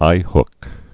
(īhk)